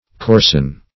Coarsen \Coars"en\ (k[=o]rs"'n), v. t.